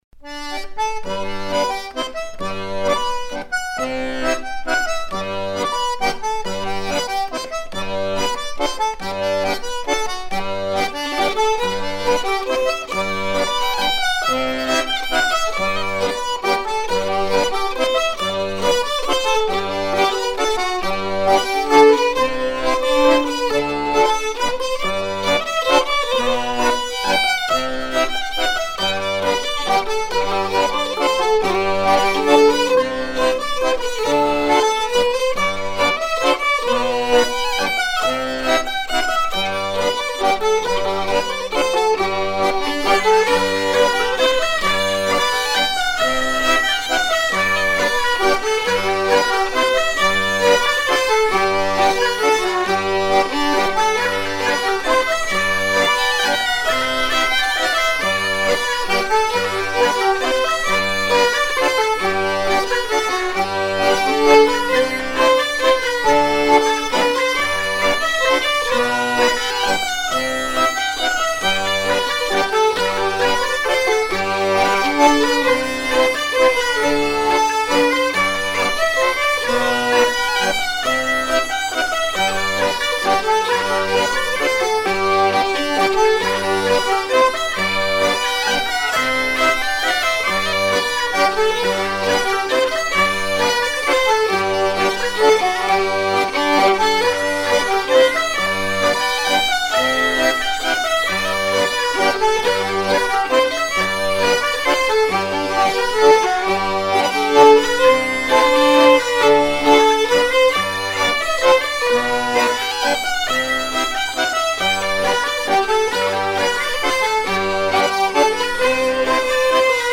Mazurka
Air de mazurka entendu dans la Sarthe